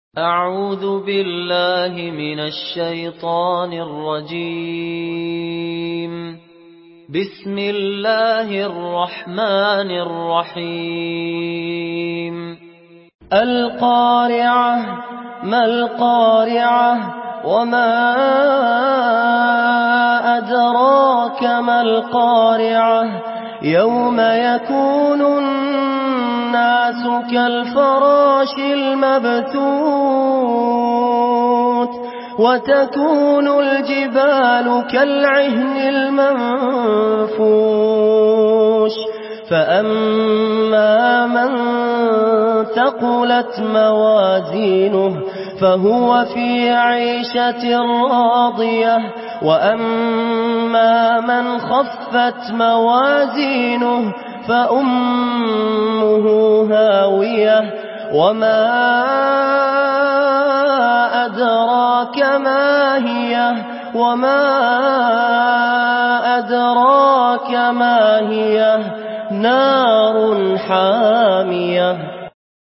سورة القارعة MP3 بصوت فهد الكندري برواية حفص
مرتل حفص عن عاصم